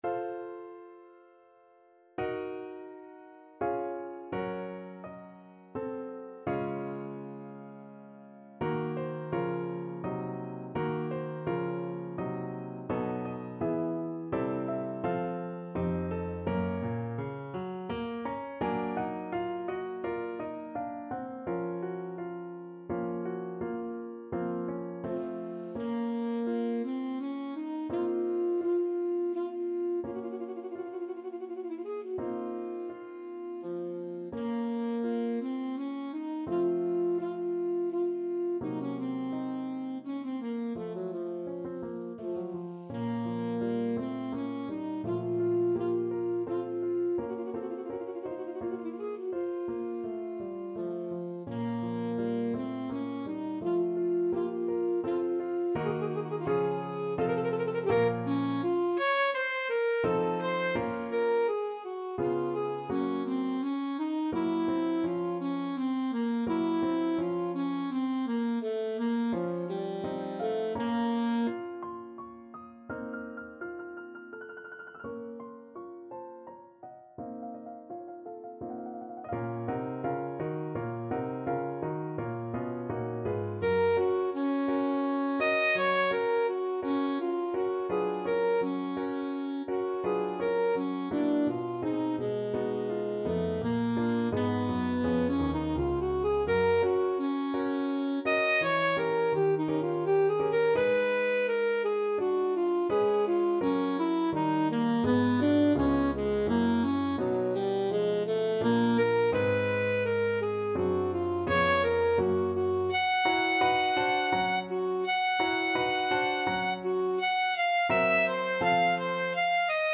Alto Saxophone
Andante =84
3/4 (View more 3/4 Music)
Classical (View more Classical Saxophone Music)